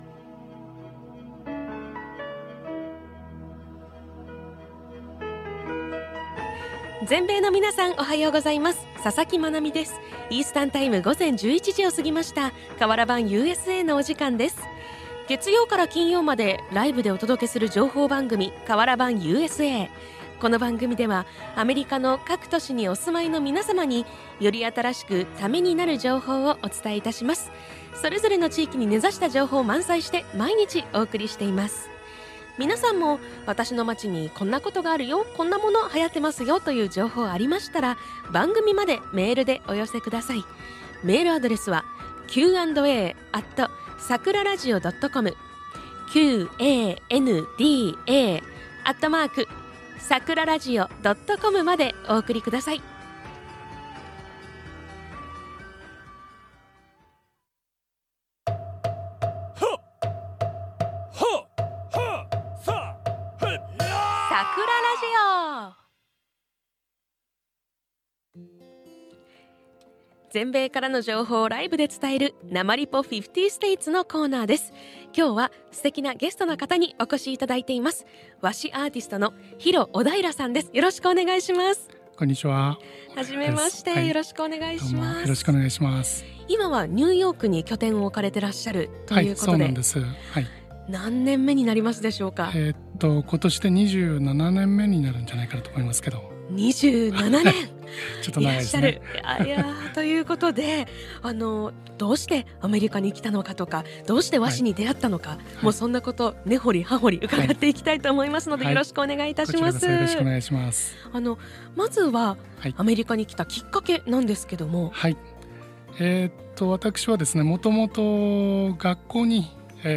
自身の来歴や創造の原点、そしてこれから紙づくりにどう携わっていきたいかなどについて話しています。下のオーディオプレーヤーで放送の録音（14MB）をお聞きいただけます。